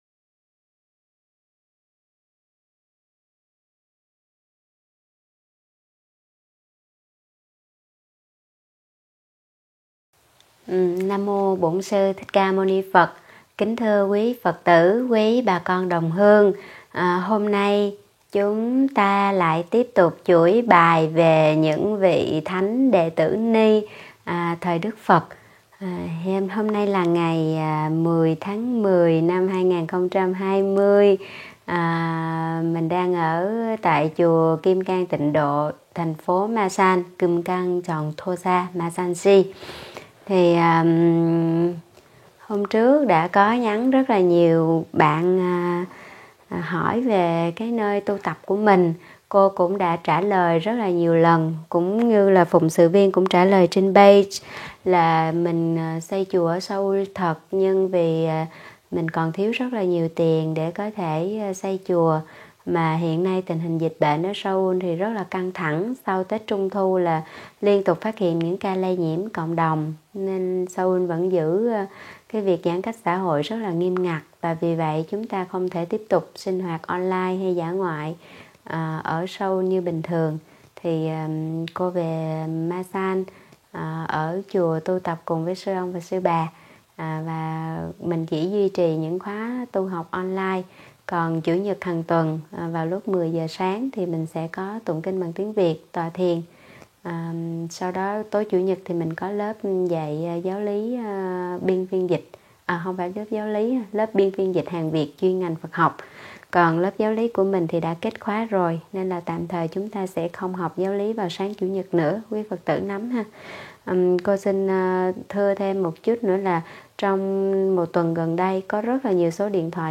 Mời quý phật tử nghe mp3 thuyết pháp Bhadda Kundalakesa